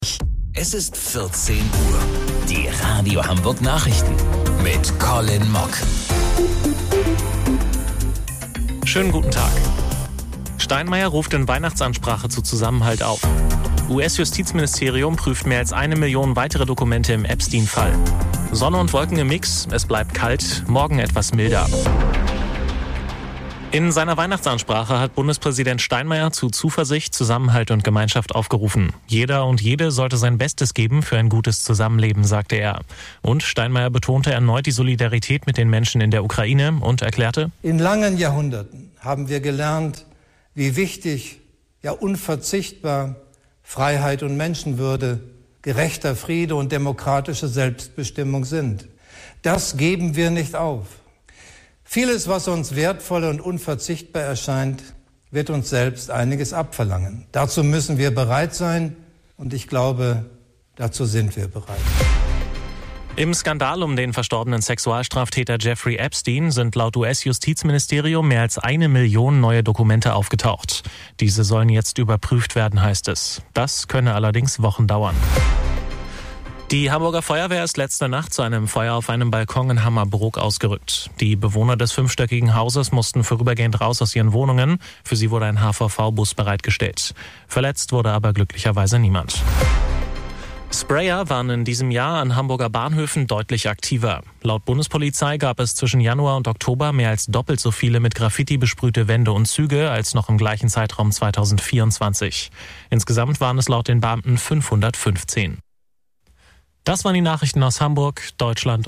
Radio Hamburg Nachrichten vom 25.12.2025 um 14 Uhr